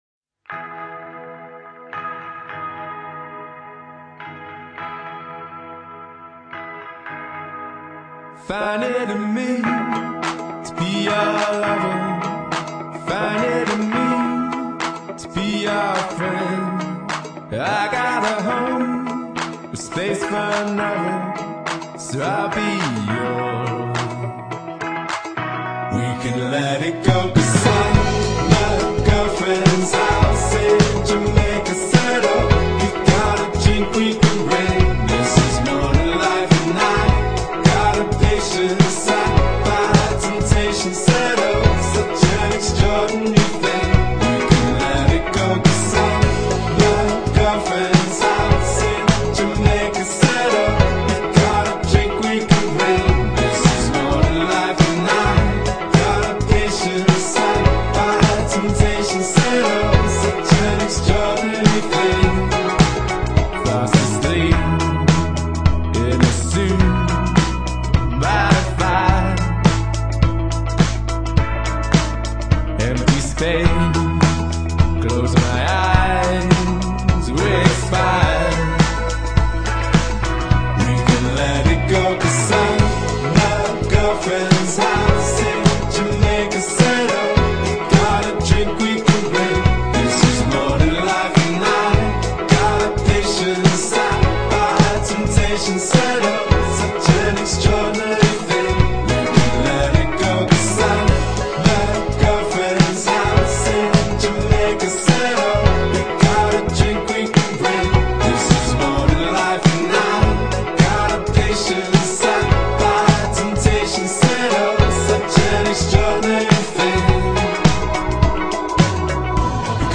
catchy summer hook